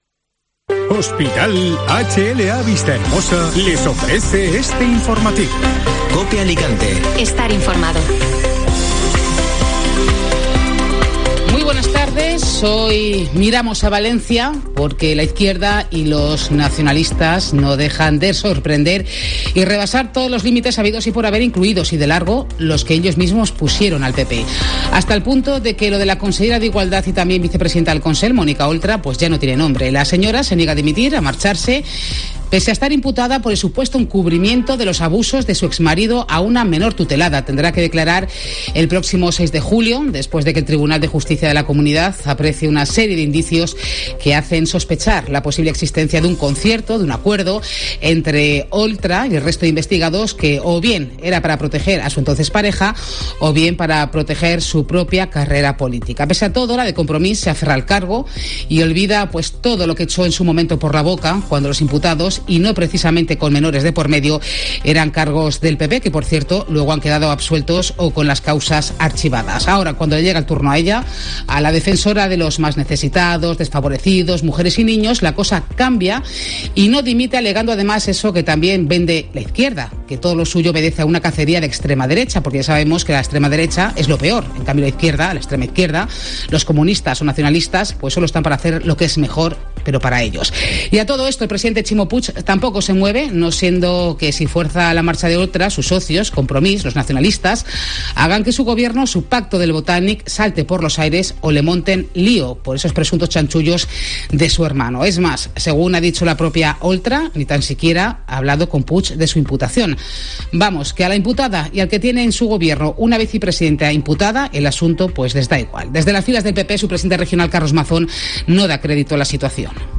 Informativo Mediodía COPE (Viernes 17 de junio)